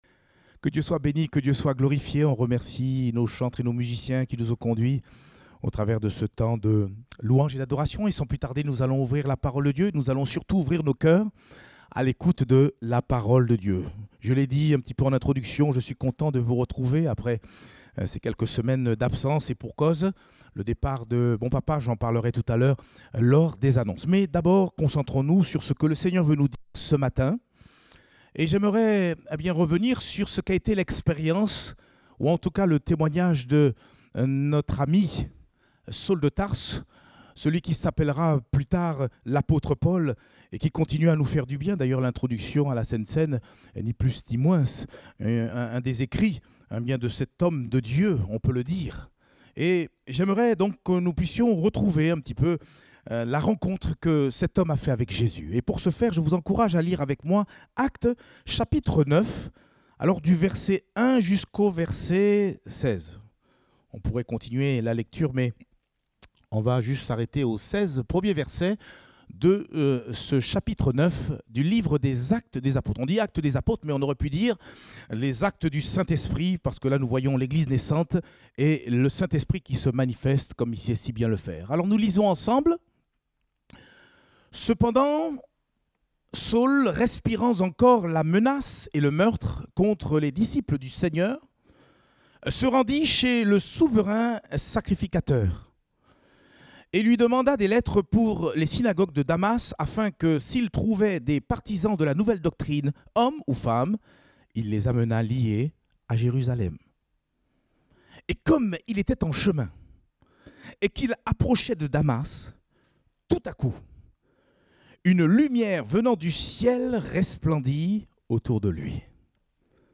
Date : 21 mars 2021 (Culte Dominical)